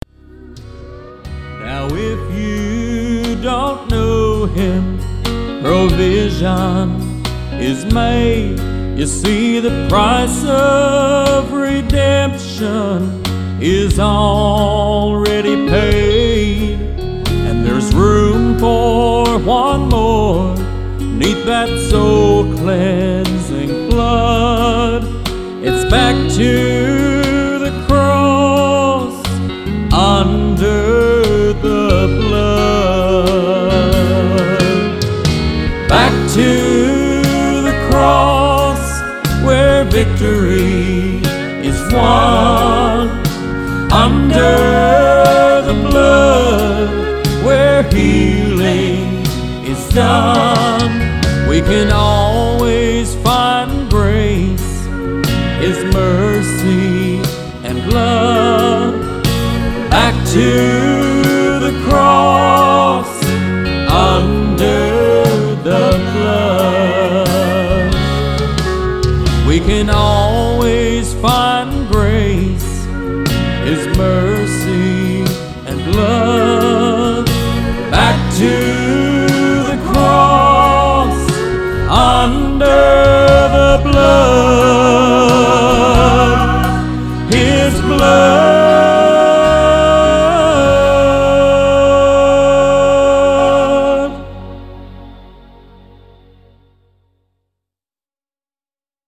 11 Southern Gospel Songs